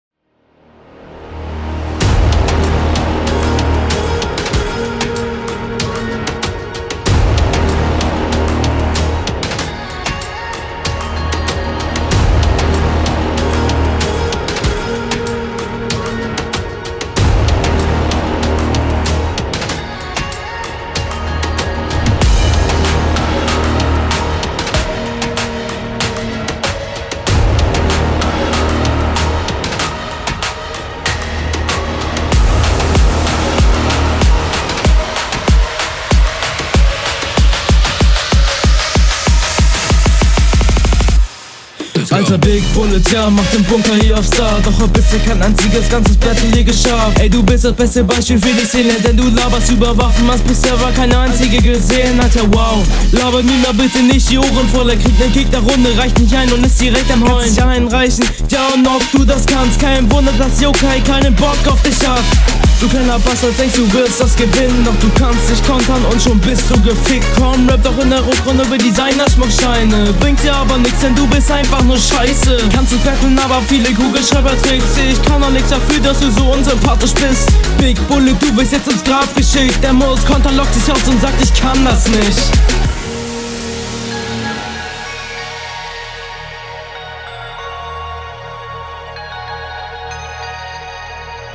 beat sehr laut